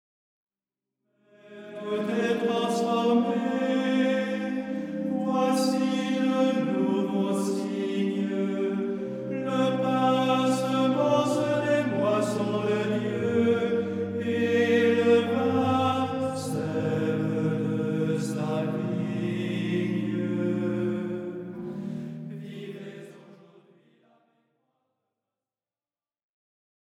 Hymnes & Tropaires
Format :MP3 256Kbps Stéréo